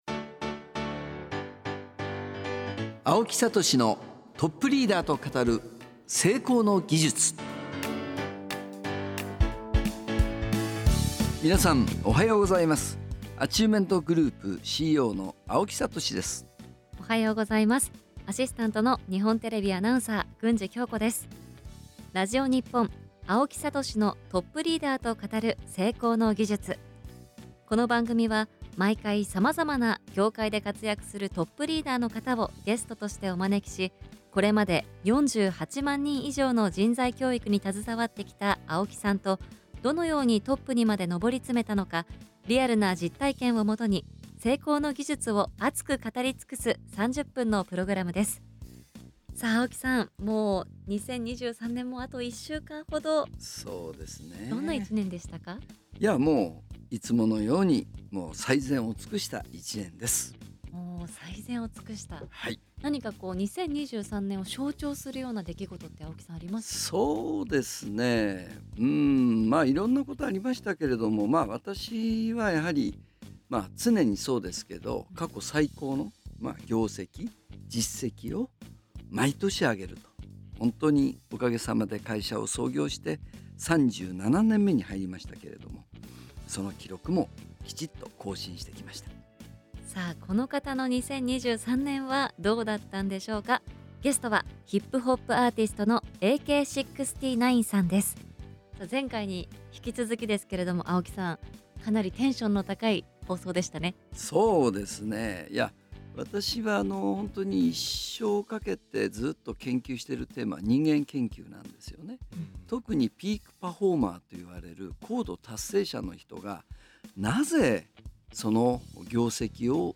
今週は ヒップホップアーティスト AK-69さんがゲストの後編です！